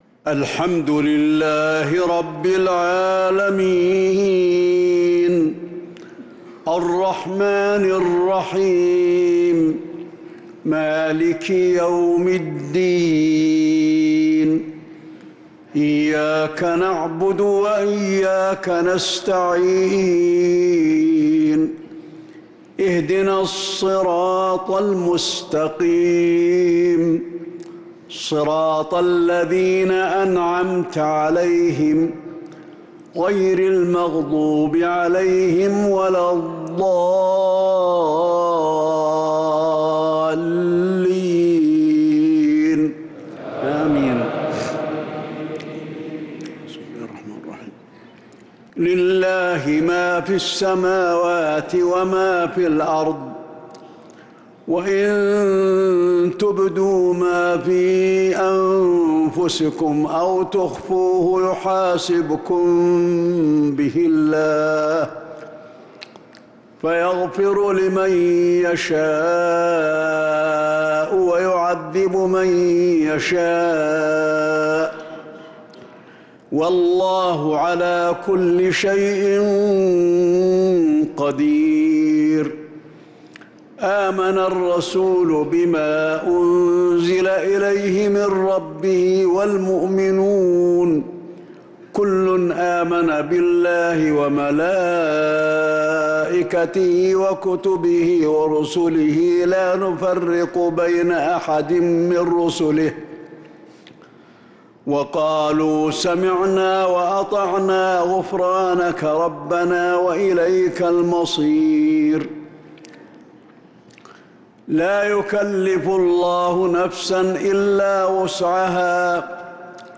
مغرب السبت 1-9-1446هـ خواتيم سورتي البقرة 284-286 و الإسراء 110-111 | Maghrib prayer from Surat al-Baqarah & Al-Isra 1-3-2025 > 1446 🕌 > الفروض - تلاوات الحرمين